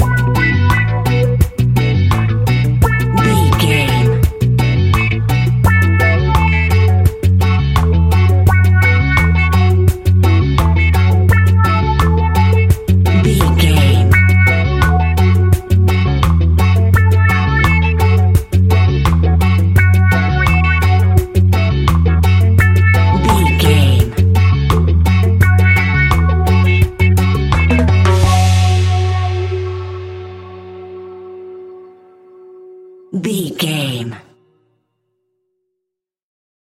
Classic reggae music with that skank bounce reggae feeling.
Aeolian/Minor
F#
instrumentals
laid back
chilled
off beat
drums
skank guitar
hammond organ
transistor guitar
percussion
horns